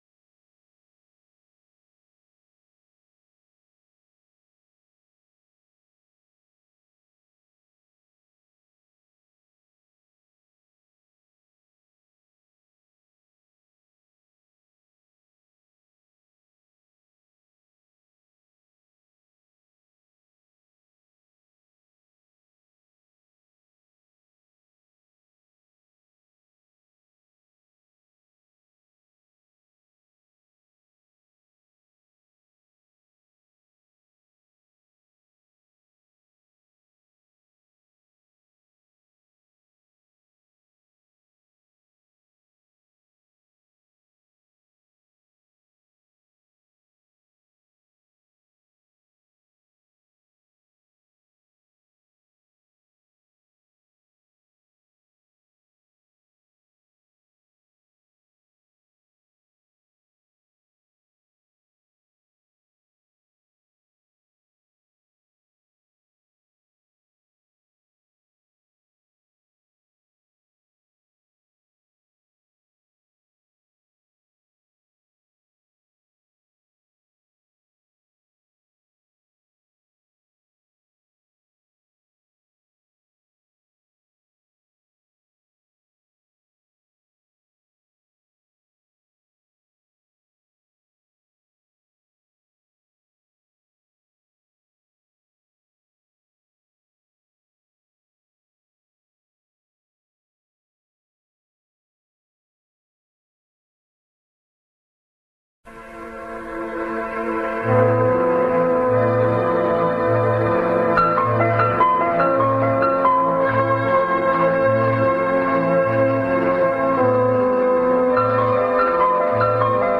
موسیقی متن و تیتراژ فیلم سینمایی